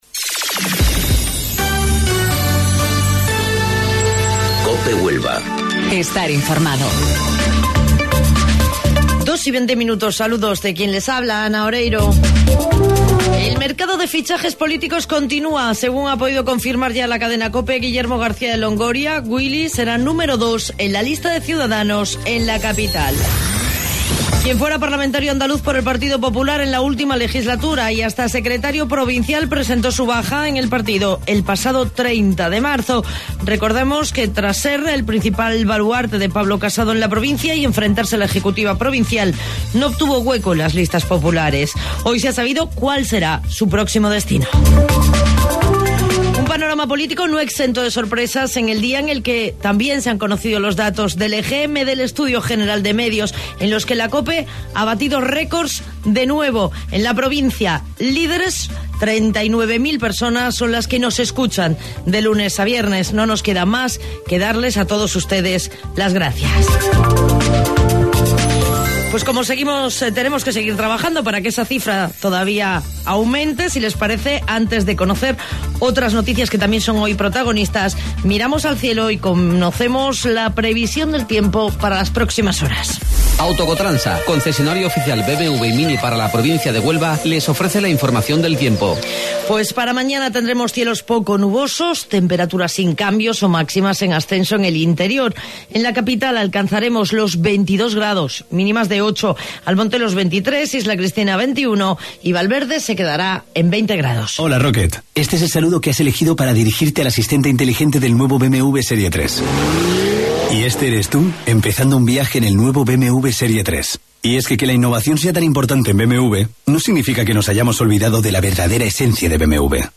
AUDIO: Informativo Local 14:20 del 10 de Abril